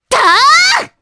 Mirianne-Vox_Attack3_jpb.wav